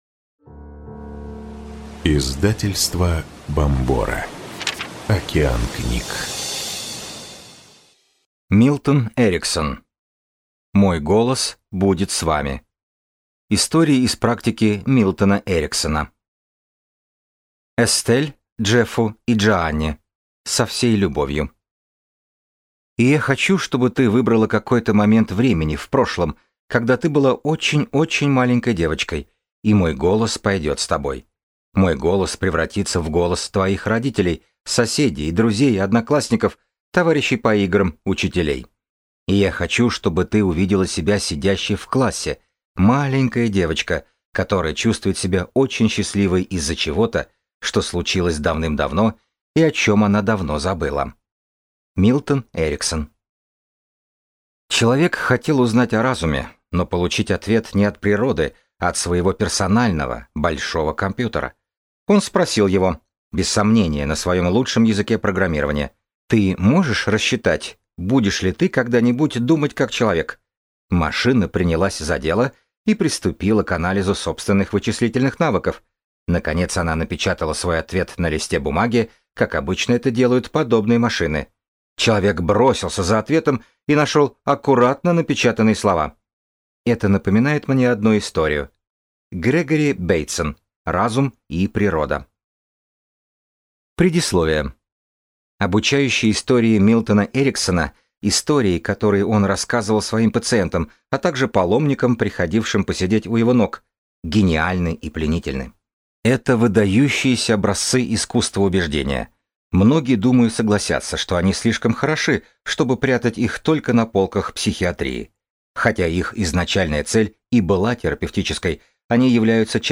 Аудиокнига Мой голос будет с вами. Истории из практики Милтона Эриксона | Библиотека аудиокниг